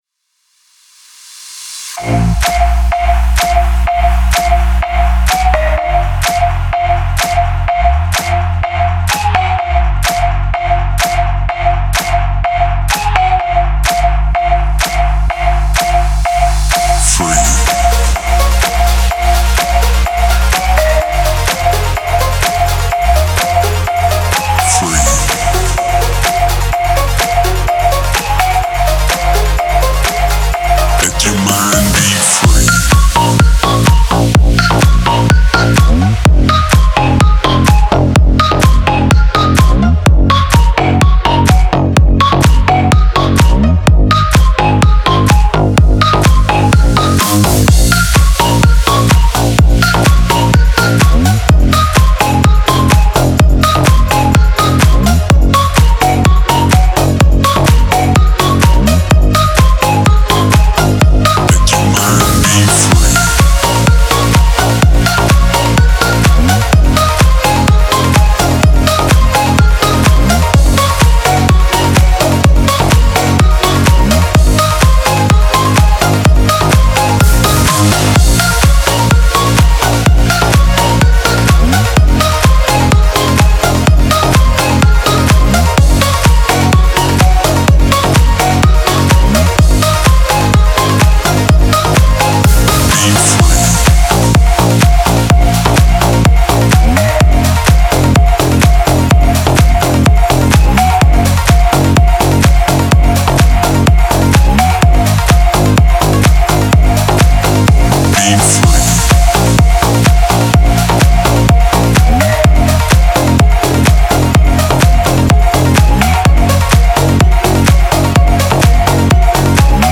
Клубная музыка
клубная музыка